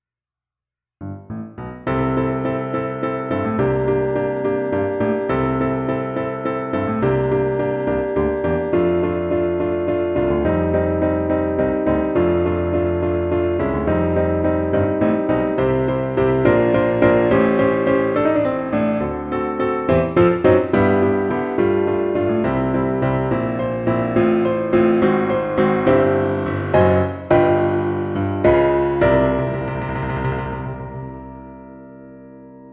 3) chords that harmonize a Left Hand walk-up are included.
5) the final I chord is prepared by a flat-II chord, a Db9.